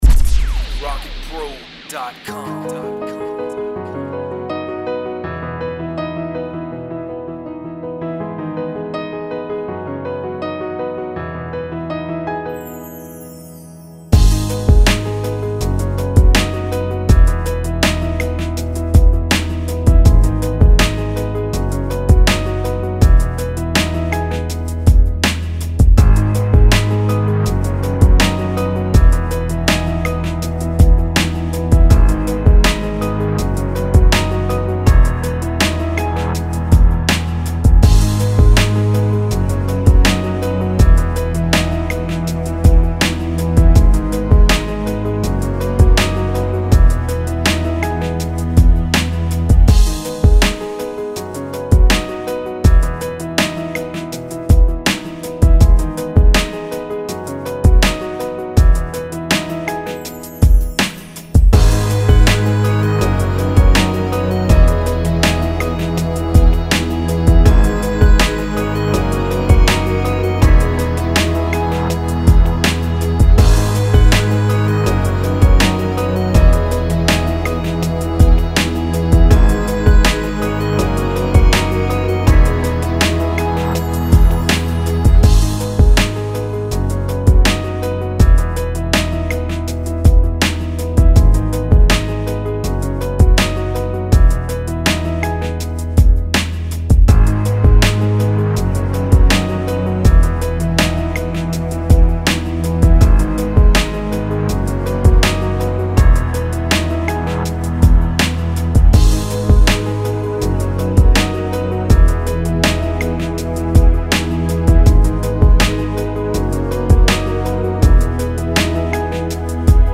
81 BPM.